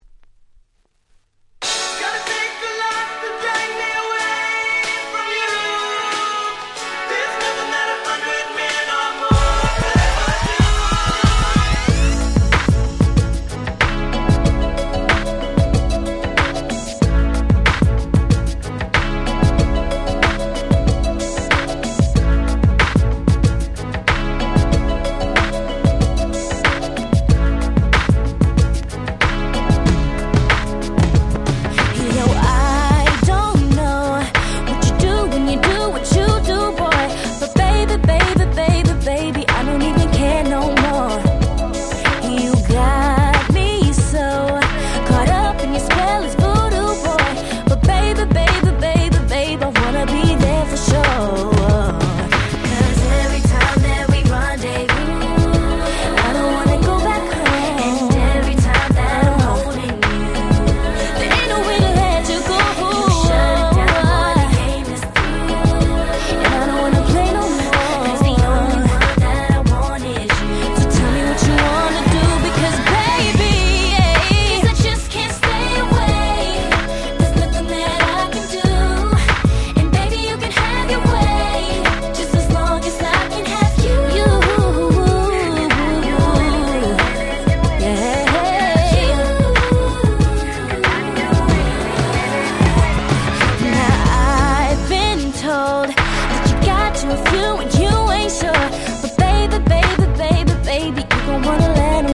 00's R&B